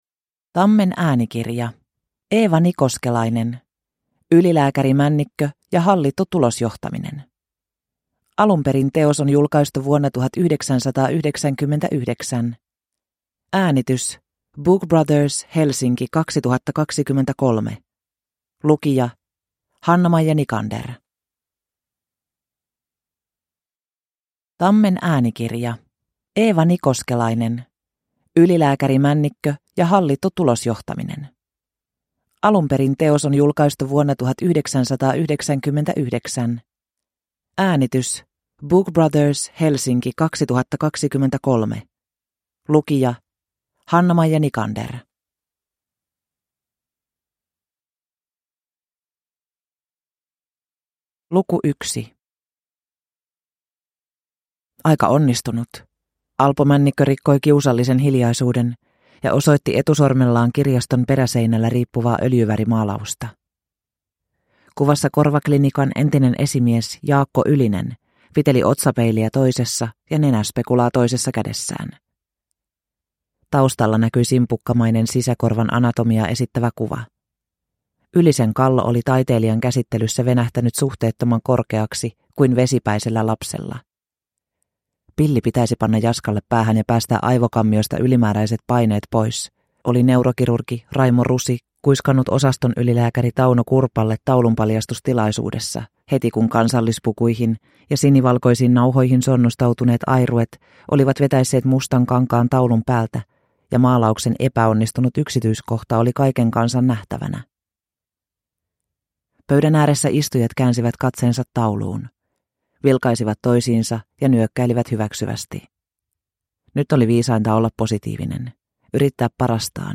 Ylilääkäri Männikkö ja hallittu tulosjohtaminen – Ljudbok